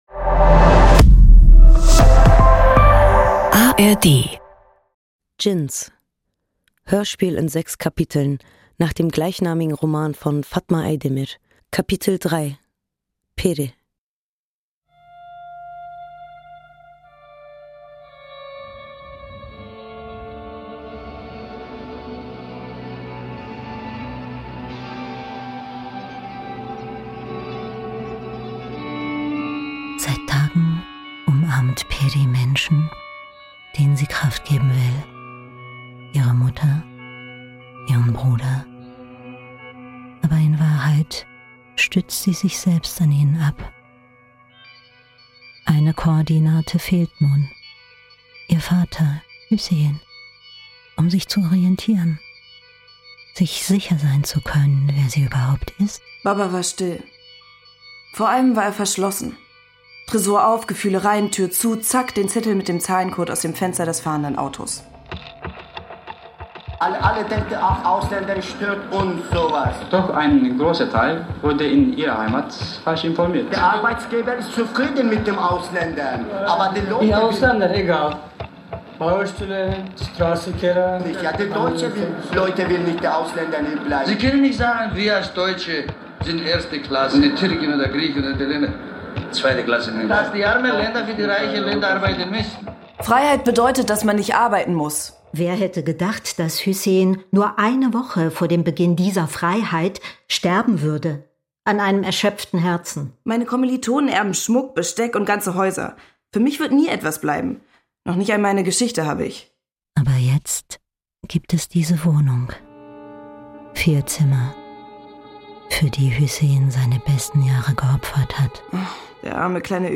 Hörspieleinrichtung und Regie